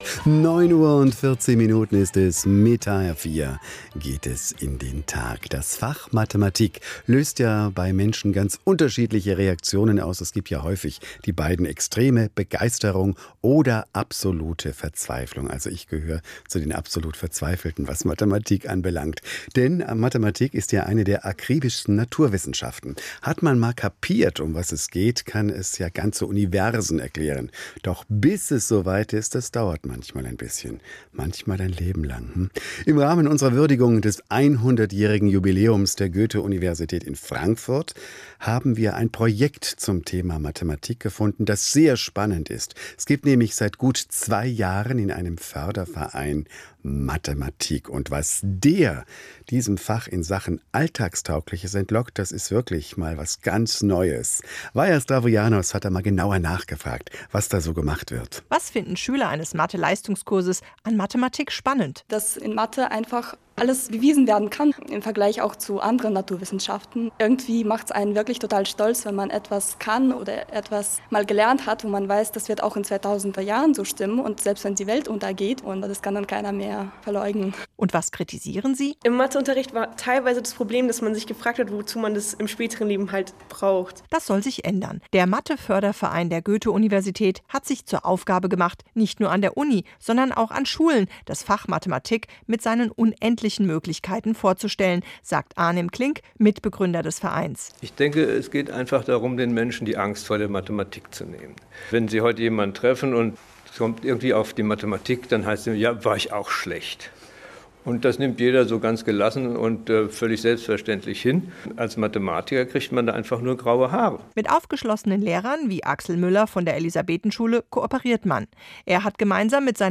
Am 12. Mai 2014 sendete der Hessiche Rundfunk in hr4 um 9:15 Uhr eine kurze Reportage über unsere Aktivitäten. Ein Thema war das Event „24 Stunden Mathe“ an der Elisabethenschule und die Ringvorlesung über Joseph Fourier.